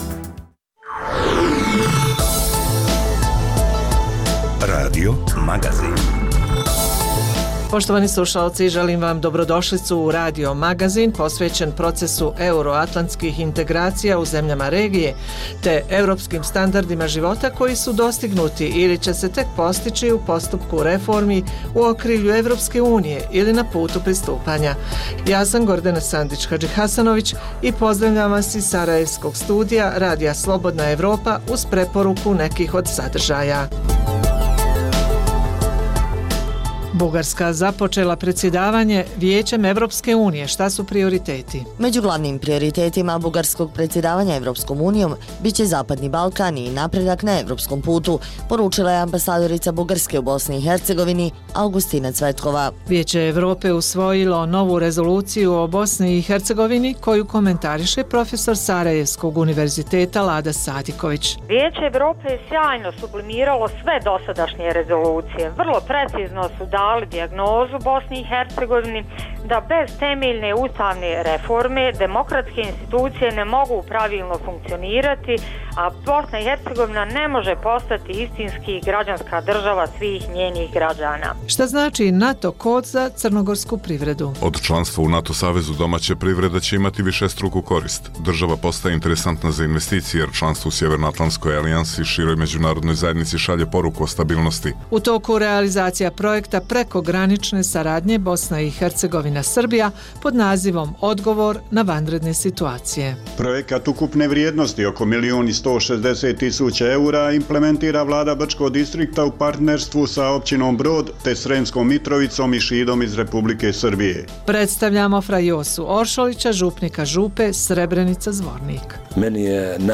Radio magazin: Na vratima Evrope